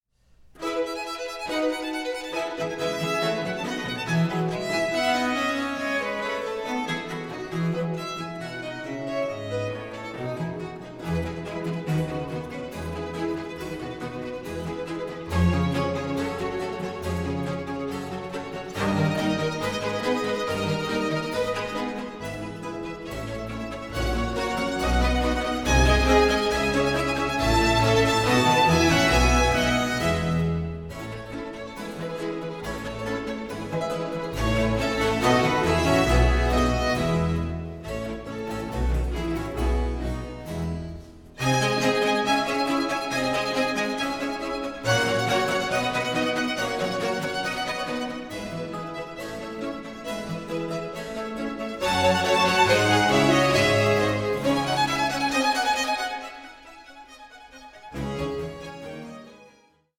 Concerto grosso in F Major, Op. 6 No. 6